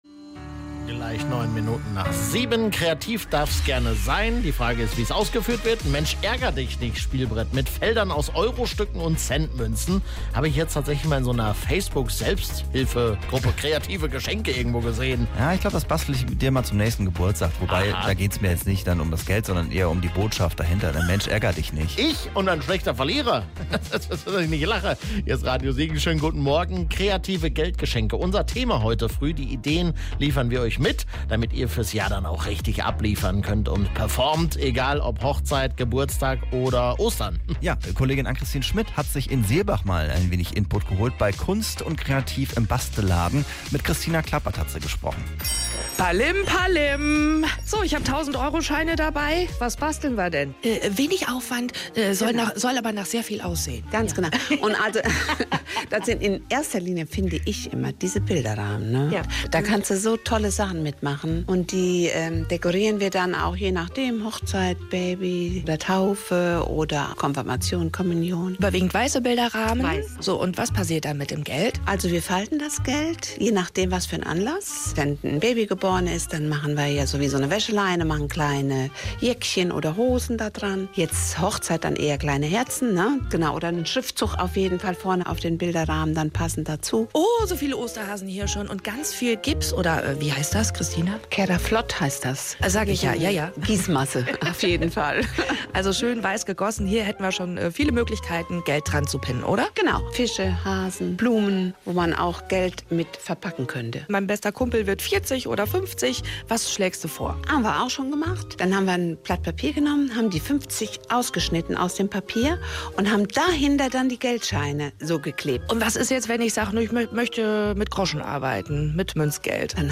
Bastelladen Interview Teil 1